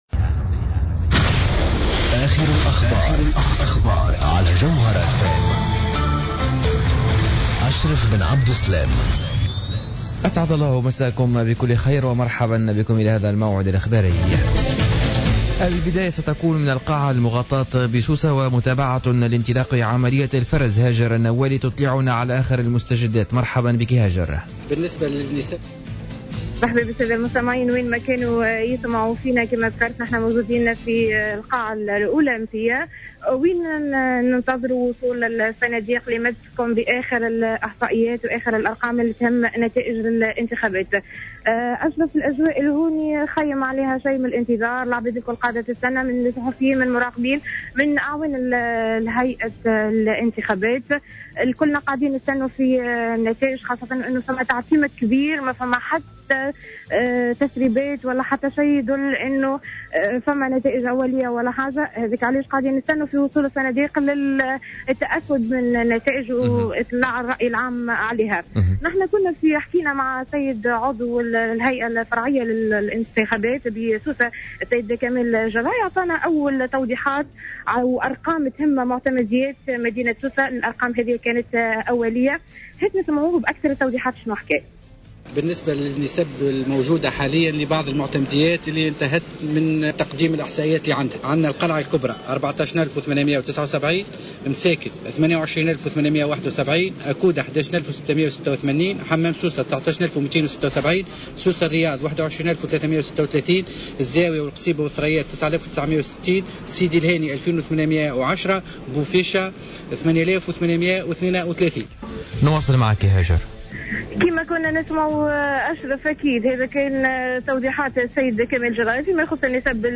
نشرة أخبار منتصف الليل ليوم الإثنين 27-10-14